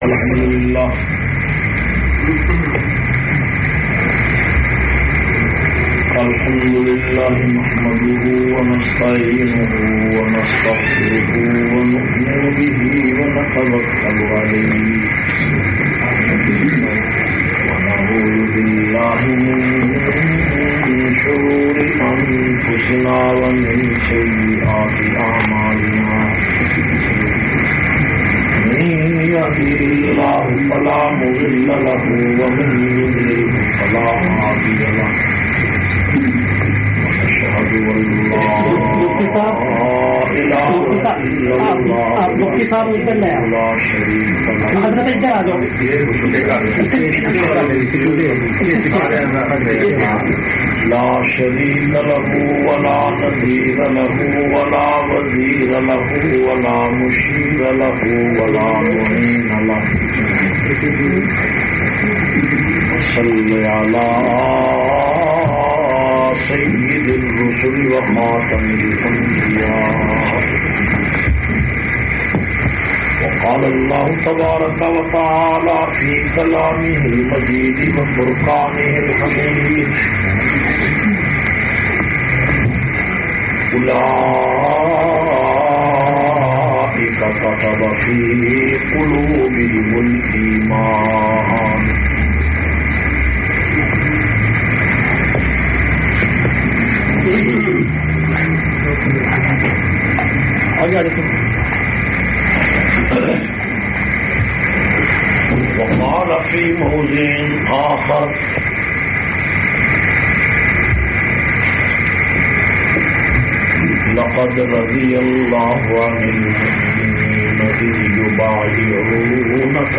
427- Nisbat e Paighamber Islamic Centre Bath Gate Scotland.mp3